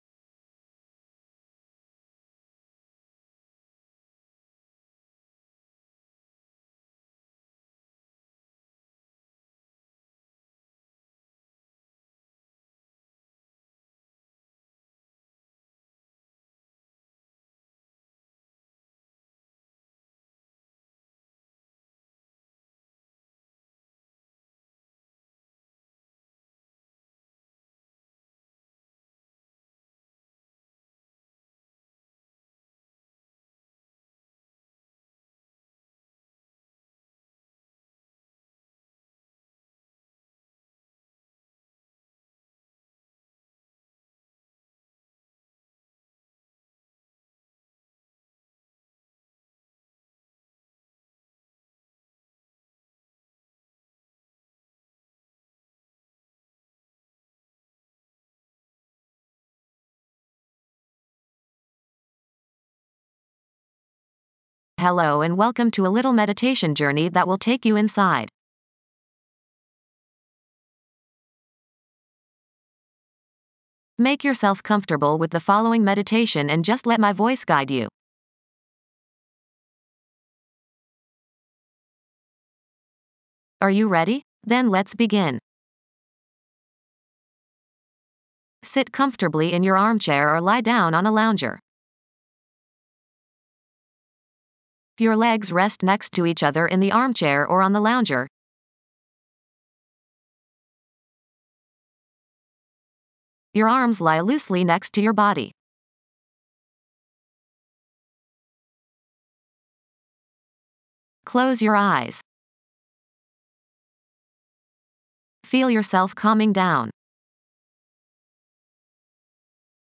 Shift this 29th Chakra 40 cm forward up and 40 backward up or 40 cm up in all four directions. Keep your focus on these passages as you let the music wash over you. Again, there is no affirmation.